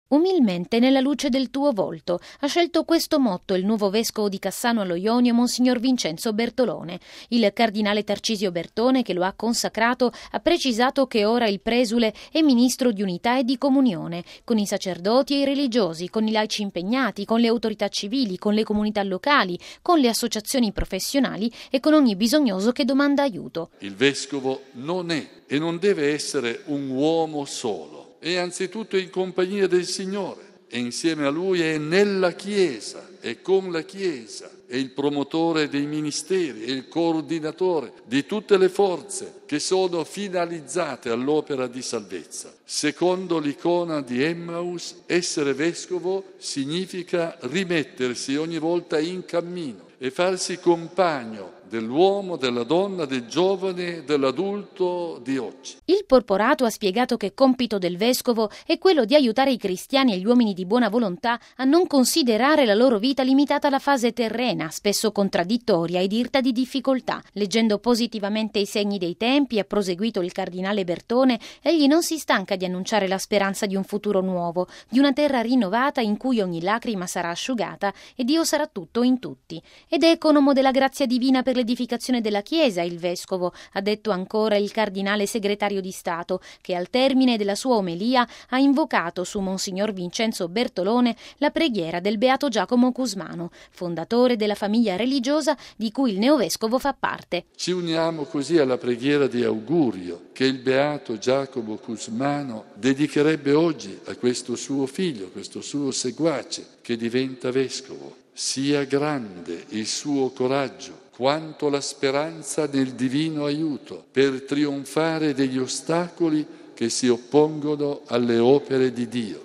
“Essere vescovo significa rimettersi ogni volta in cammino e farsi compagno dell’uomo di oggi”: è quanto ha sottolineato ieri pomeriggio, nella Basilica Vaticana, il cardinale segretario di Stato, Tarcisio Bertone, durante la celebrazione per l’ordinazione episcopale di mons. Vincenzo Bertolone, nuovo vescovo della diocesi calabrese di Cassano allo Jonio.